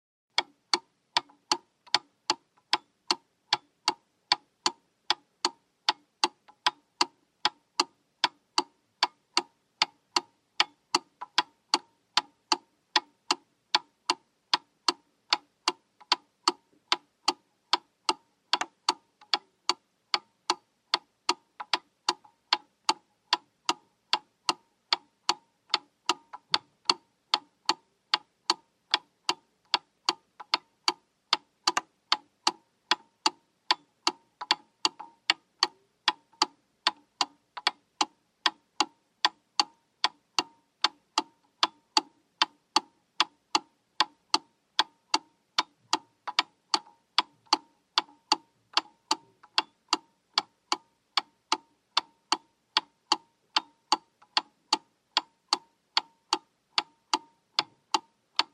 Звук_ тиканье настенных часов с кукушкой
• Категория: Настенные и напольные часы с кукушкой
• Качество: Высокое